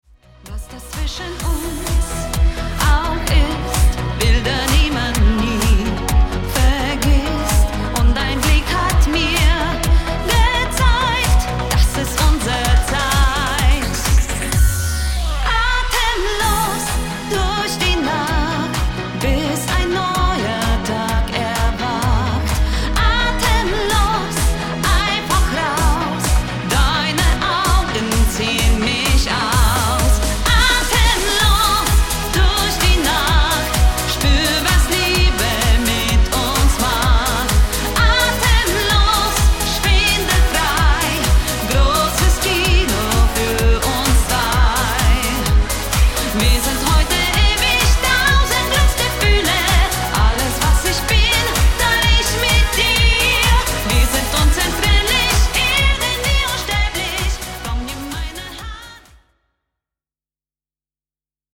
Coverversion